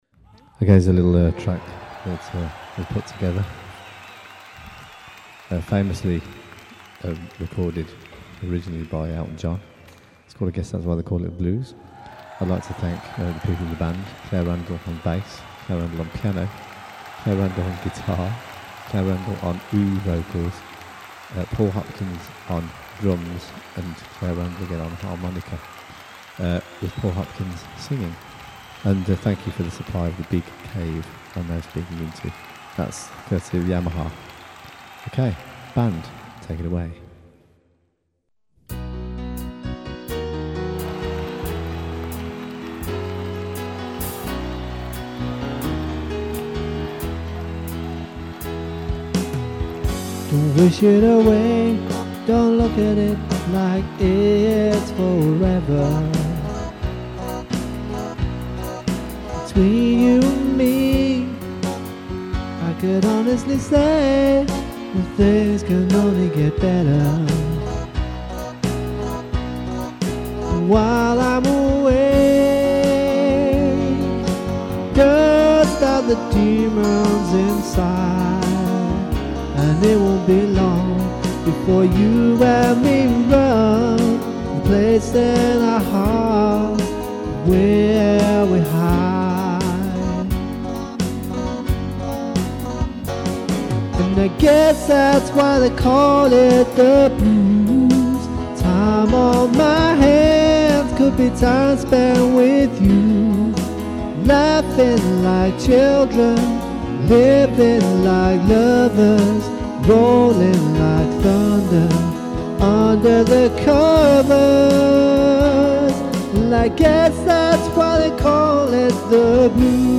Piano, Electric Guitar, Base, Harmonica
Me on Drums and vocal.